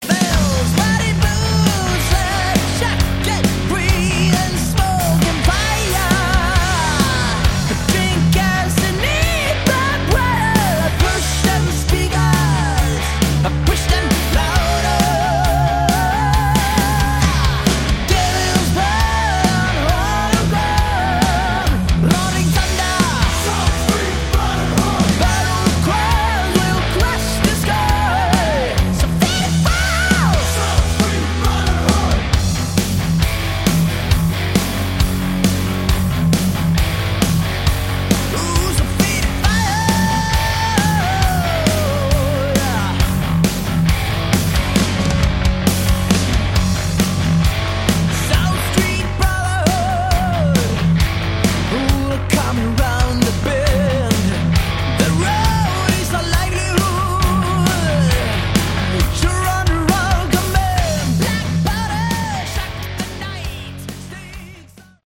Category: Melodic Metal
vocals
guitars
bass
drums